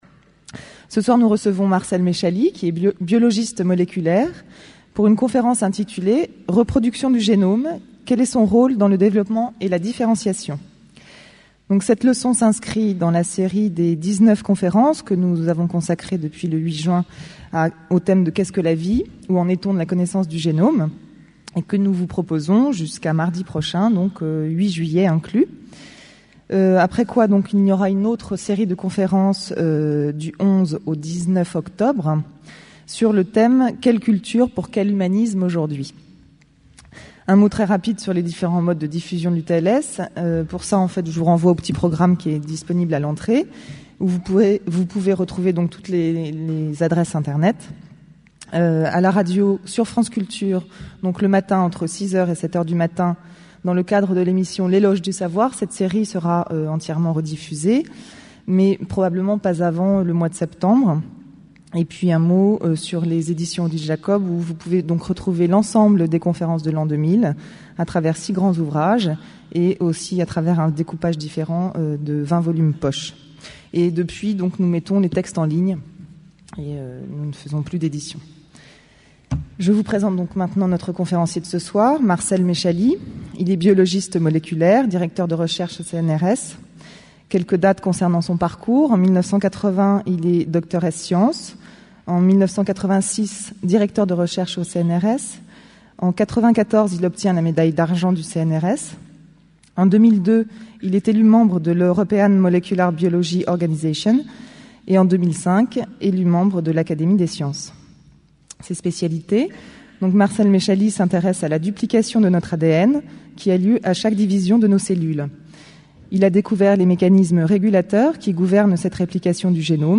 Une conférence du cycle : Qu'est ce que la vie ? Où en est la connaissance du génome ?